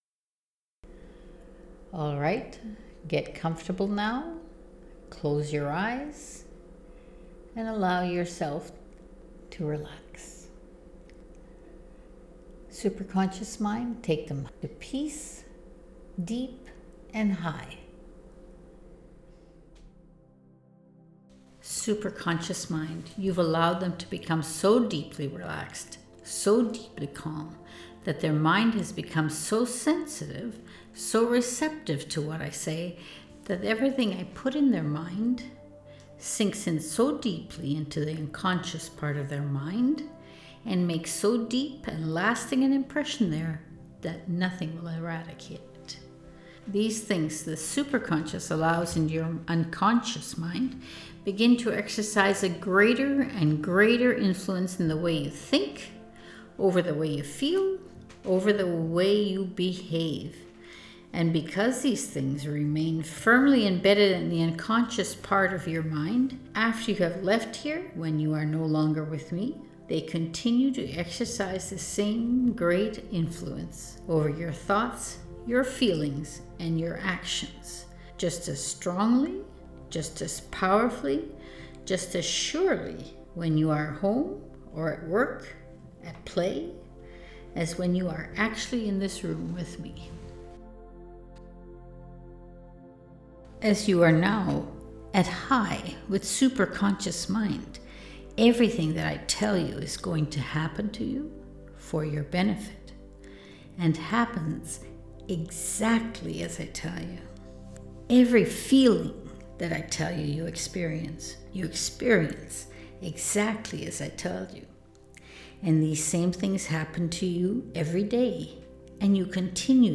Self Confidence and Well Being Self Hypnosis